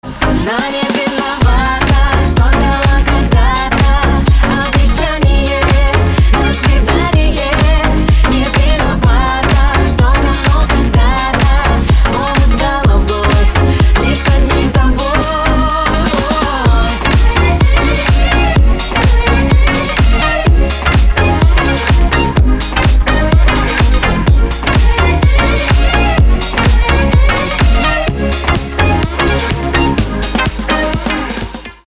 • Качество: 320, Stereo
поп
громкие
красивые
женский вокал
dance